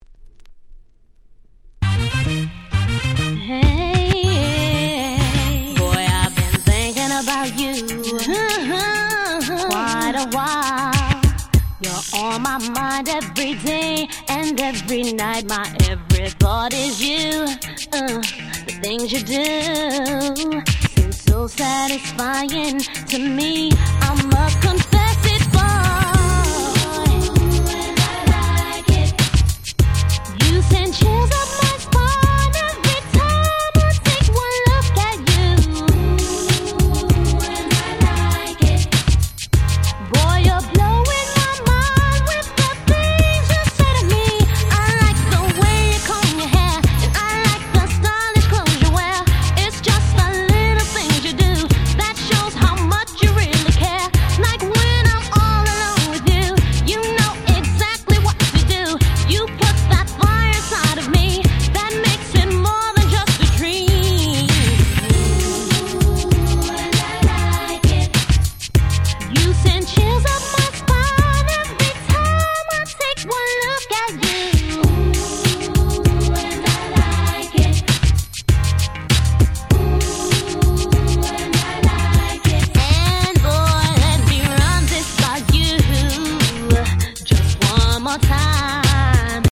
90's R&B